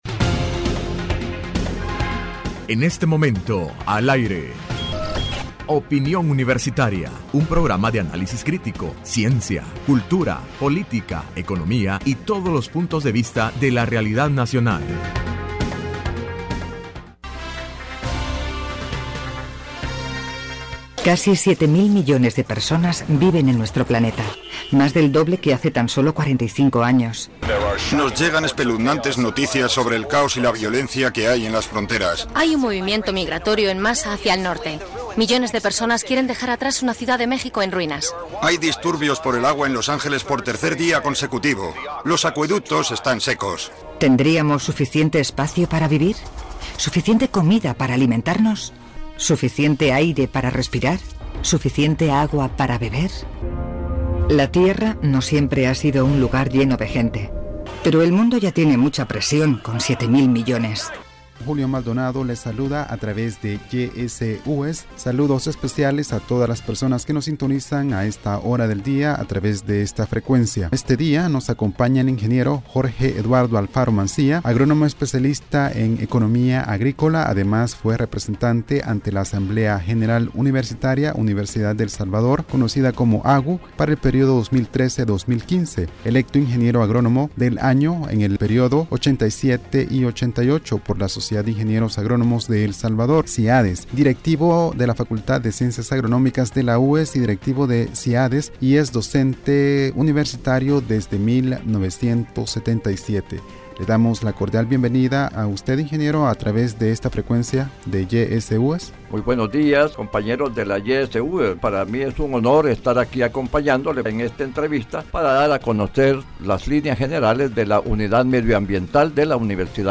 Entrevista Opinión Universitaria(13 Junio 2016) : Creación de la Unidad de Medio Ambiente en la UES.